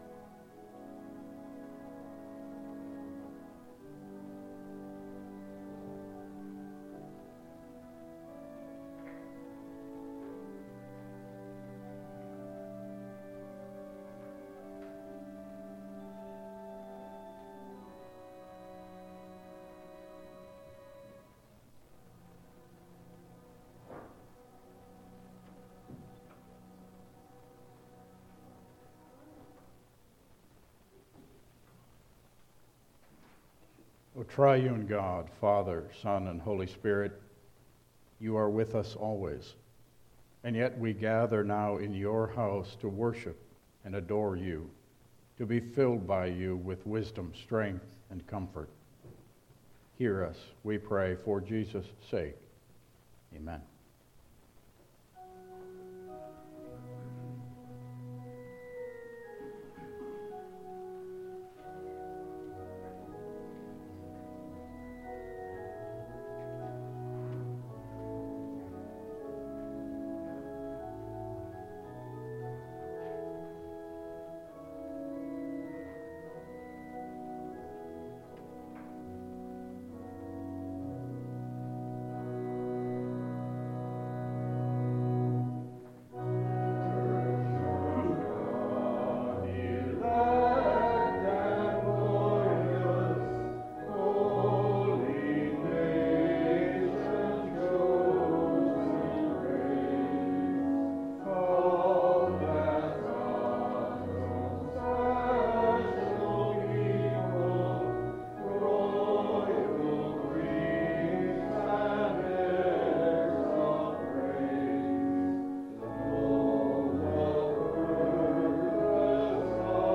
Passage: Matthew 14:22-33 Service Type: Regular Service